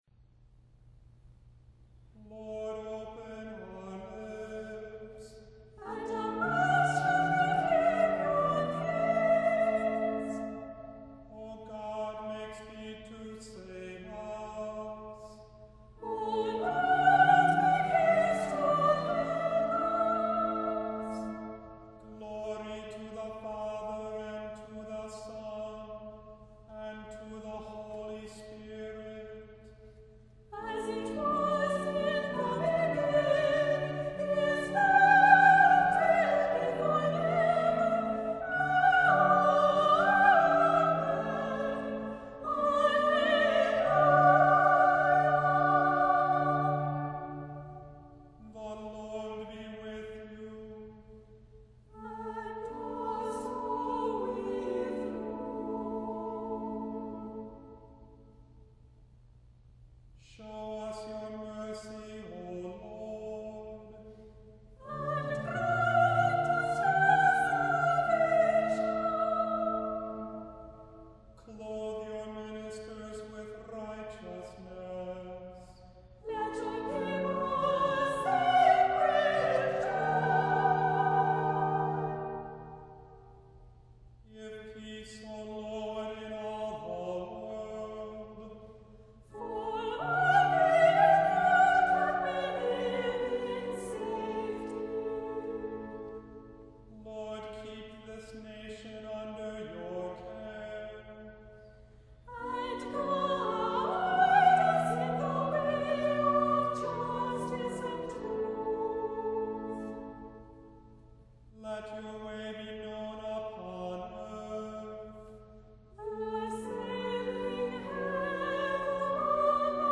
• Music Type: Choral
• Voicing: Treble Voices
• Accompaniment: Organ
• Liturgical Celebrations: Preces and Responses, Evensong
• choral writing is mostly unison with some two-part writing
• upward leaps create the sense of joy and prayer